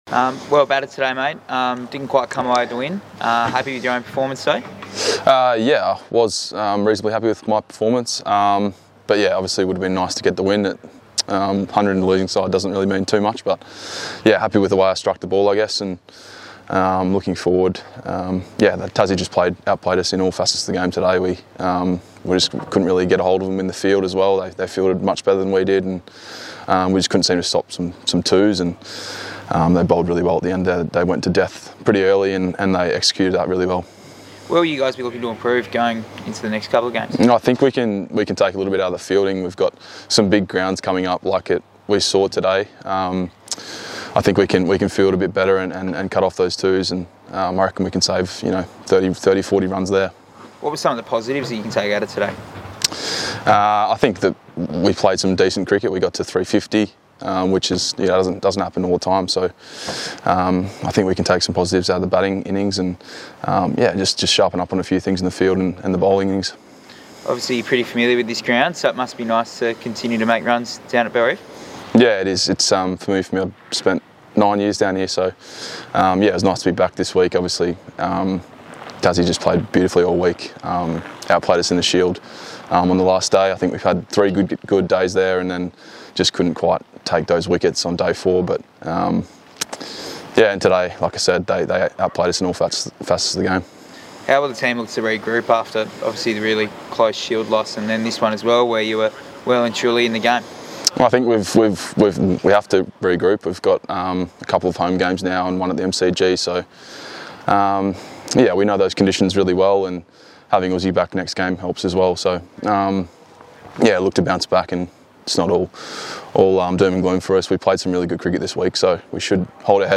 Ben McDermott speaks following Bulls defeat against Tasmanian Tigers
Queensland Bulls opening batsman Ben McDermott discussing his 143 runs today in the Bulls’ defeat against the Tasmanian Tigers. McDermott hit 13 fours and 2 sixes in his innings going at a strike rate of 127.68.